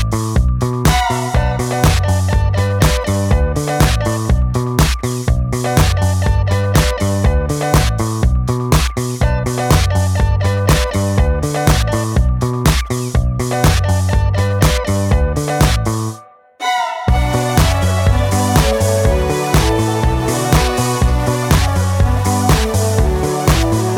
no Backing Vocals With Vocoder Pop (1990s) 3:08 Buy £1.50